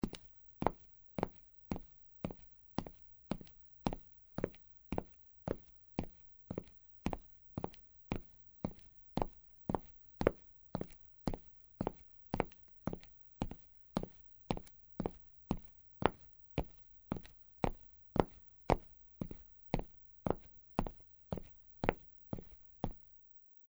普通的行走－YS070525.mp3
通用动作/01人物/01移动状态/普通的行走－YS070525.mp3